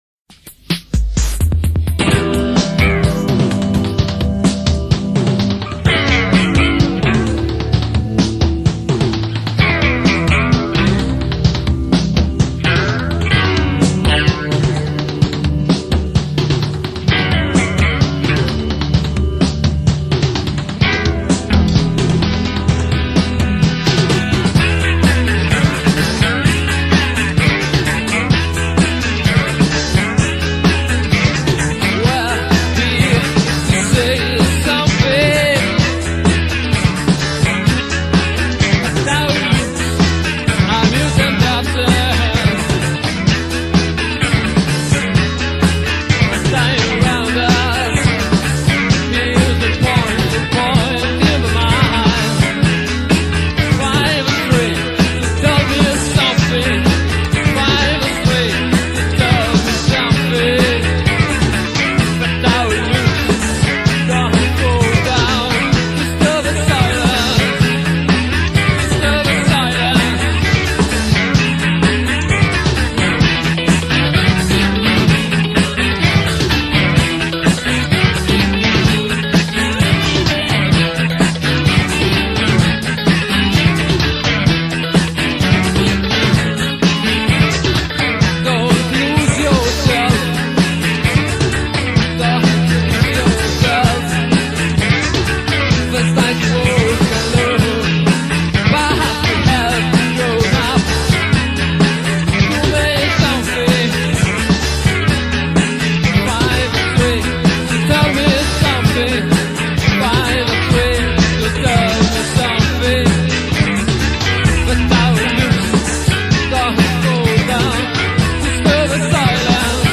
• Centro Sportivo di Lobbi (AL)